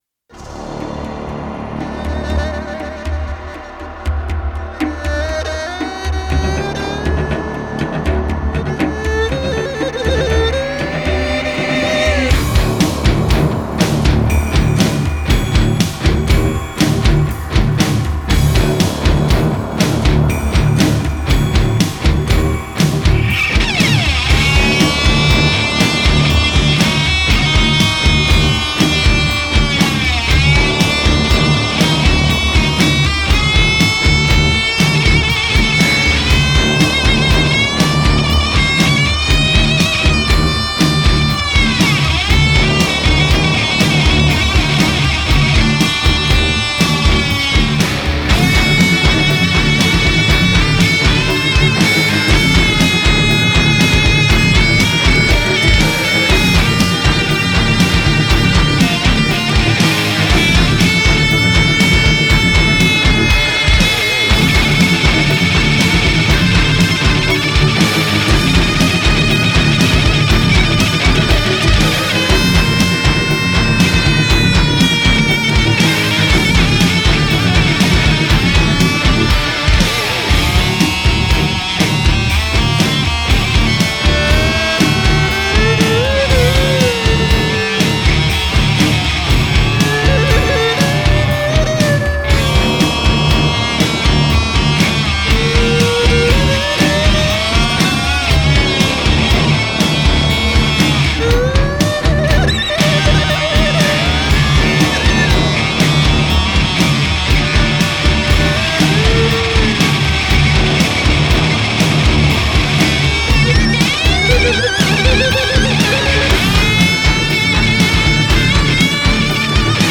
Nu Metal